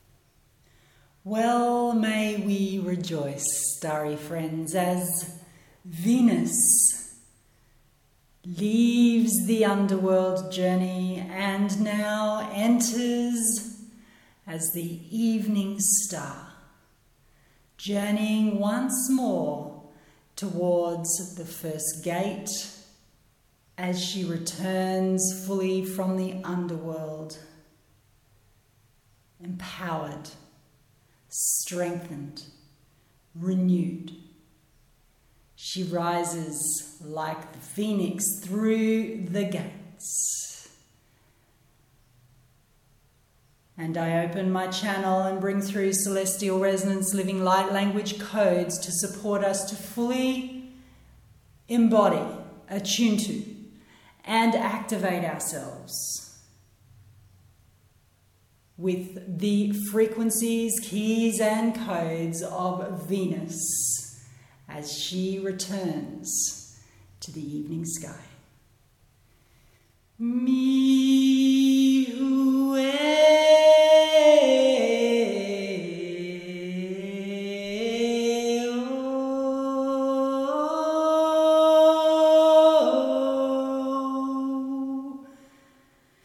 Here is a sneak peak of this transmission – it shares the first 1 minute of audio.